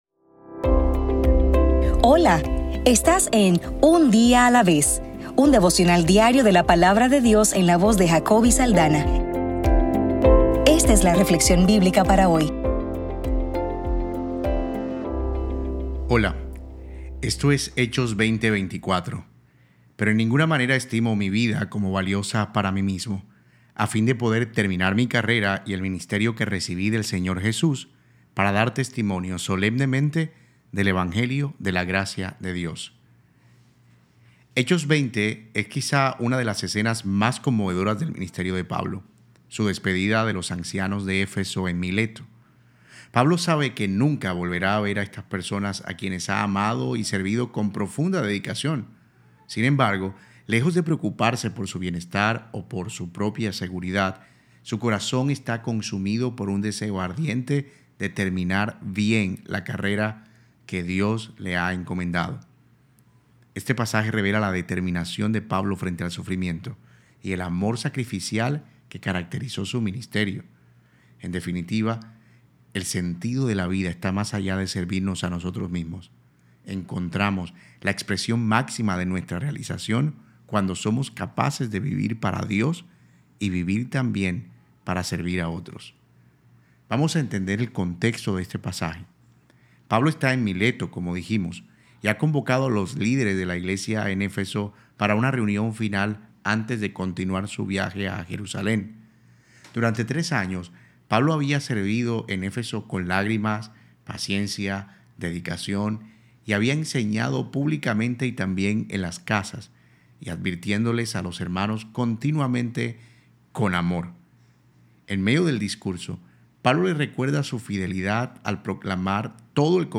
Devocional para el 20 de enero